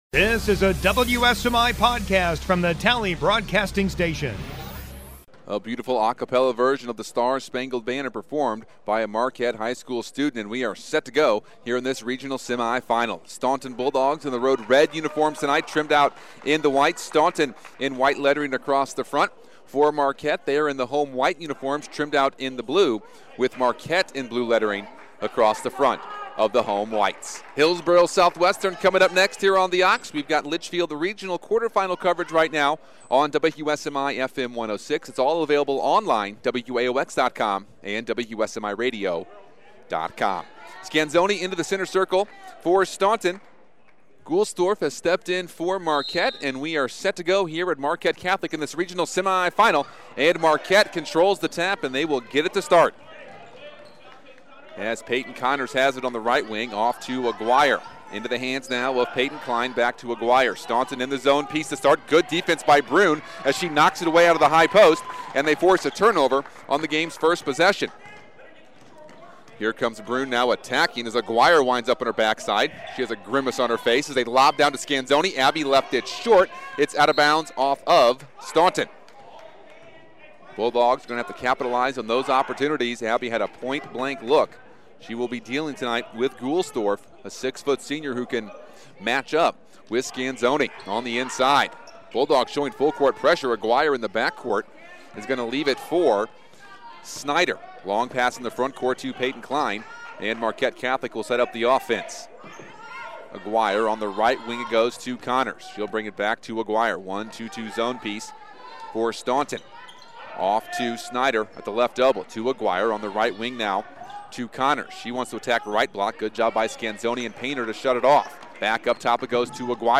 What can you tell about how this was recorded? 02/06/2018 Girls High School Basketball 2A Alton Marquette Regional Semifinal Staunton Bulldogs vs. Marquette Catholic Explorers